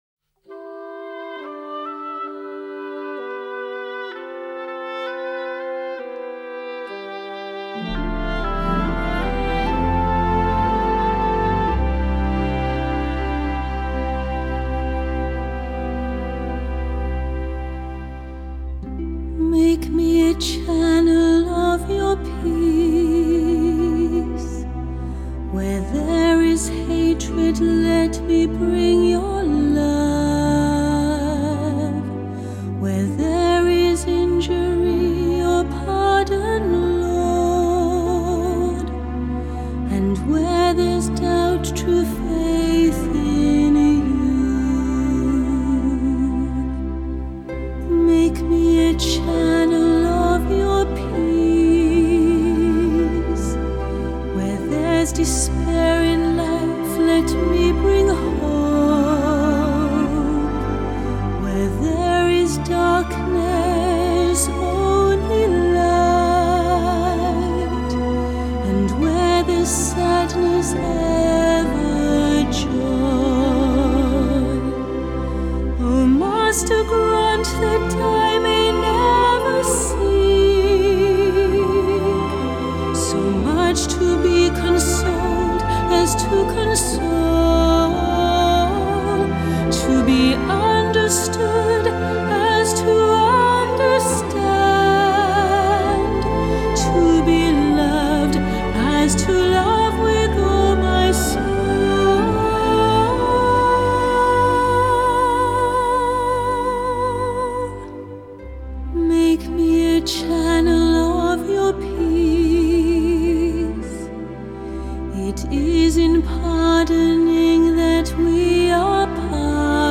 Crossover